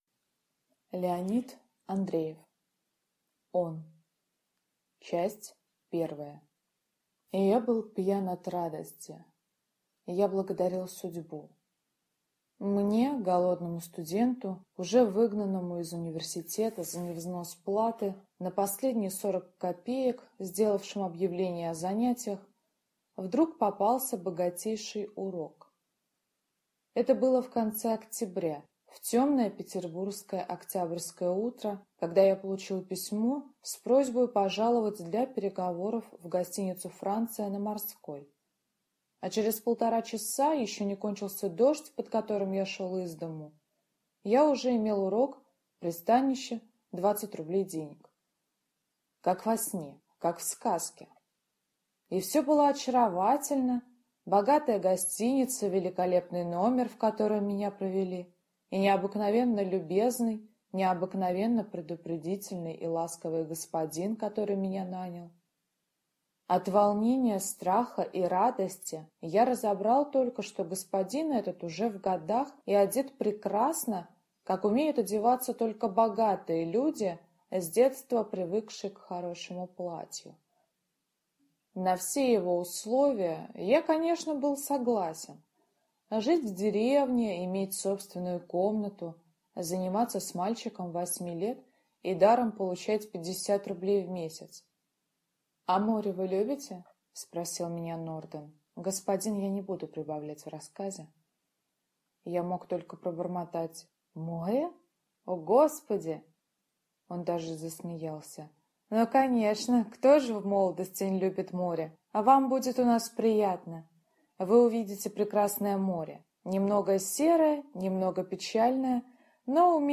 Аудиокнига Он | Библиотека аудиокниг